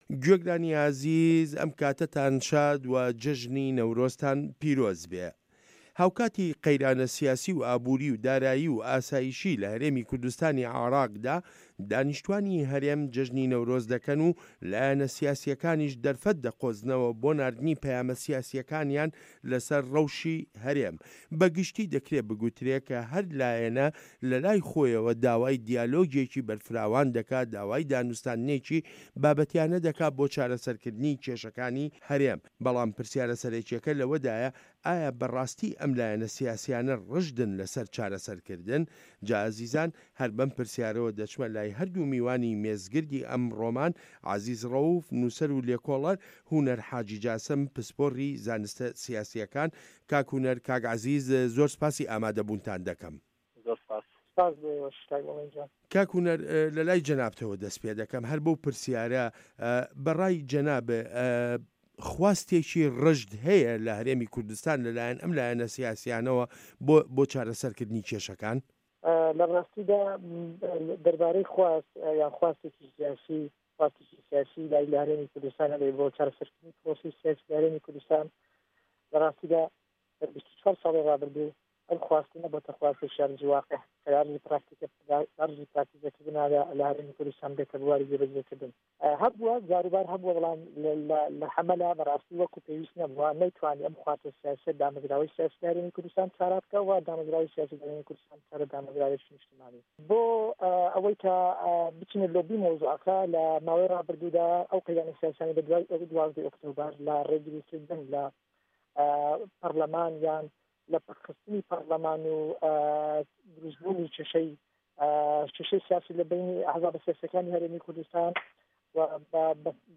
مێزگرد: نەورۆز ڕۆژێک بۆ پەیامی سیاسییانەی لایەنەکانی هەرێم